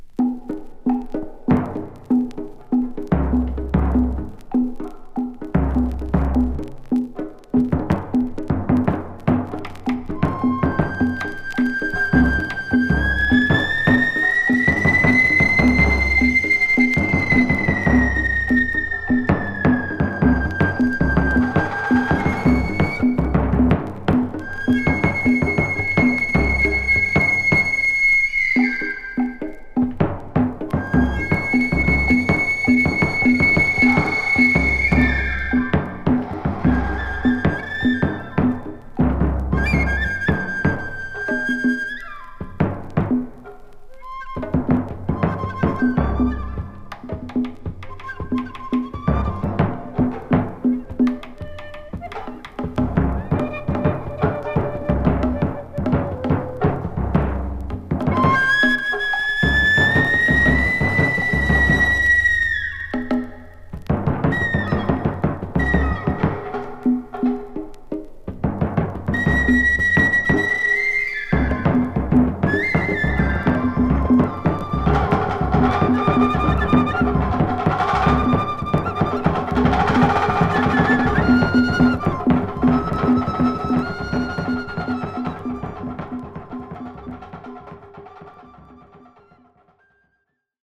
フォルクローレに世界各地の民族楽器の響きが木霊する圧巻の名腰！